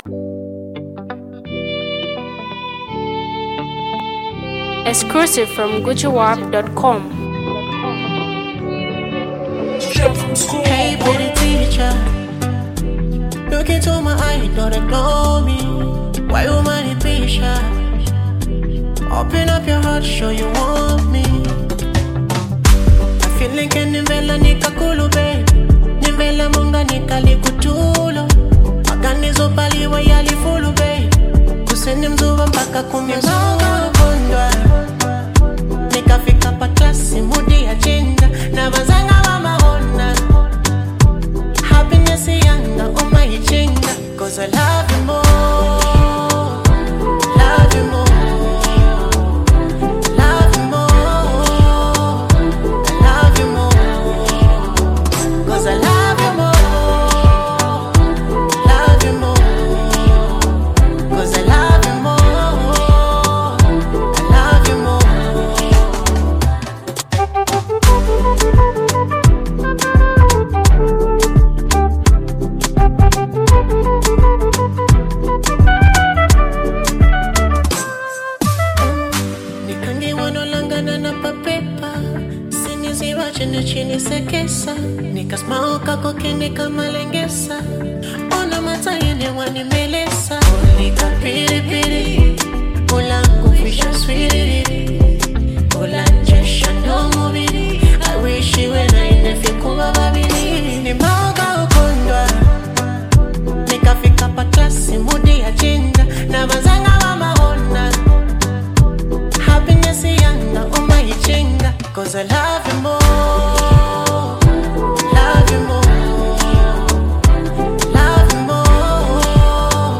soulful vocals
enchanting melodies
With its irresistible rhythm and timeless message